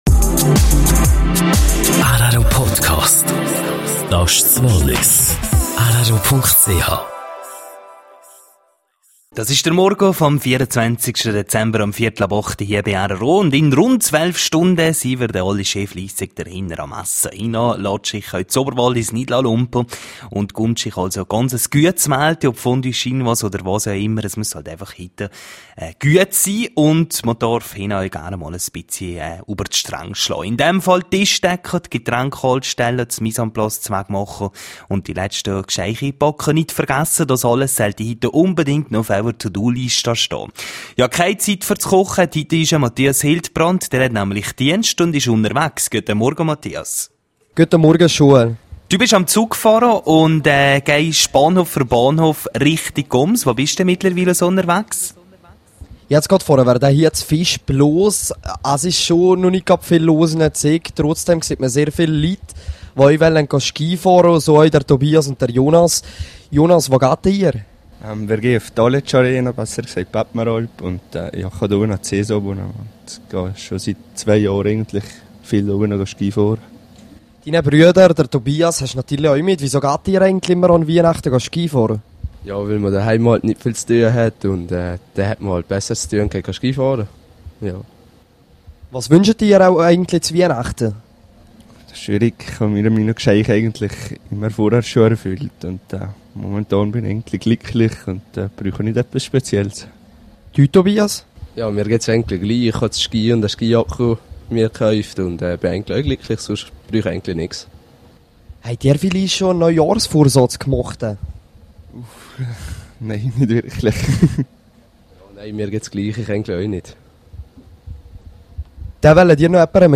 rro unterwegs im Zug: Interview